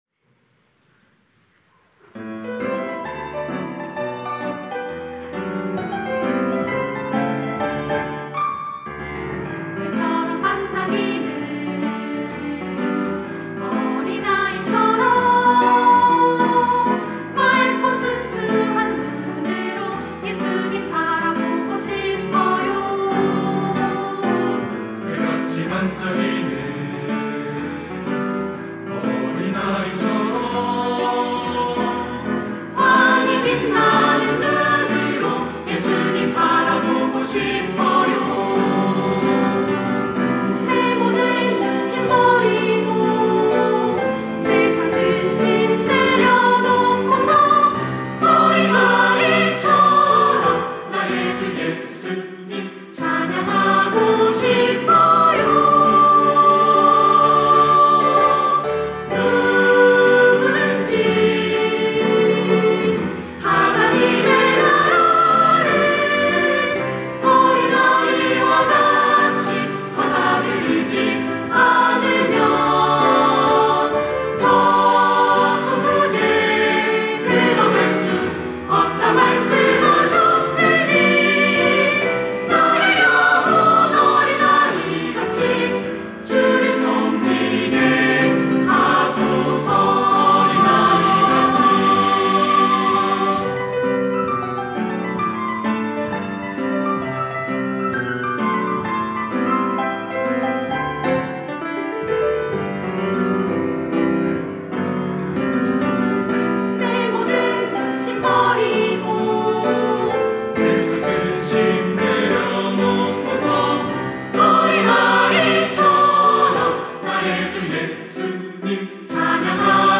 특송과 특주 - 어린아이와 같이